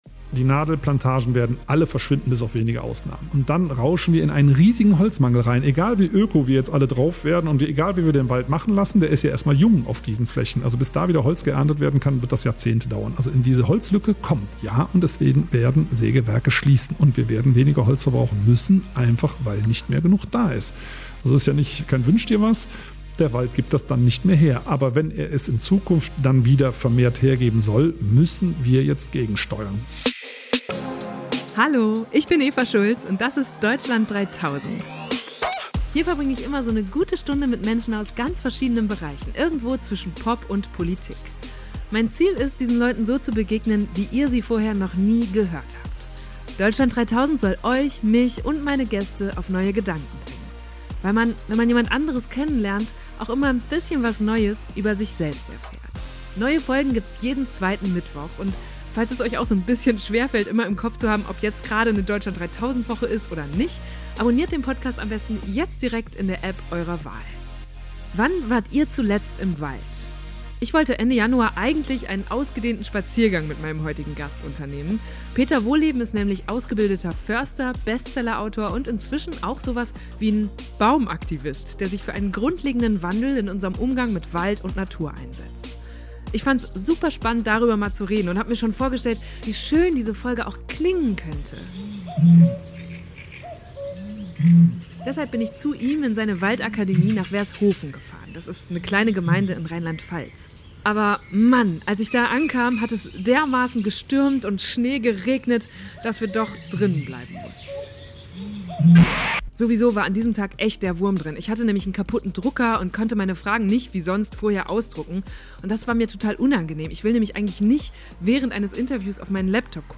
Deshalb bin ich zu ihm in seine Waldakademie nach Wershofen gefahren, das ist eine kleine Gemeinde in Rheinland-Pfalz. Aber als ich da ankam, hat es dermaßen gestürmt und schneegeregnet, dass wir doch drinnen bleiben mussten.